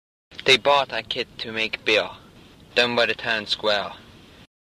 Speaker with uvular R (Drogheda)
DEA_Drogheda_Uvular_R.mp3